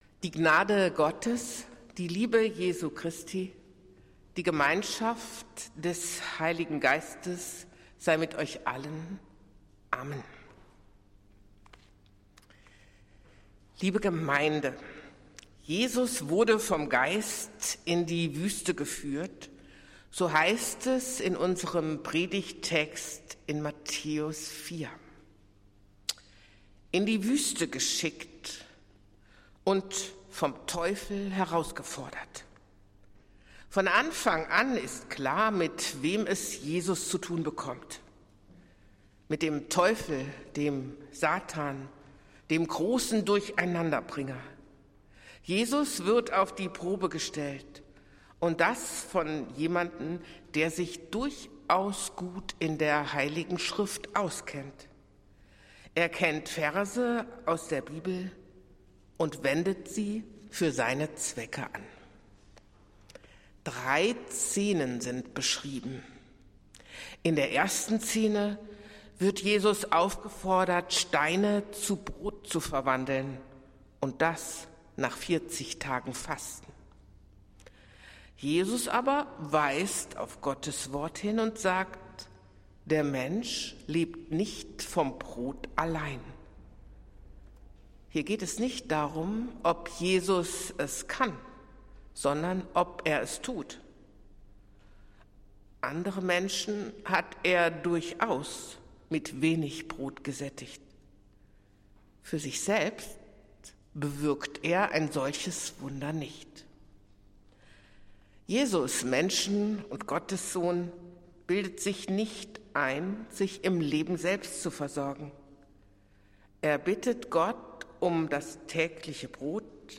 Predigt des Gottesdienstes aus der Zionskirche am Sonntag, den 18. Februar 2024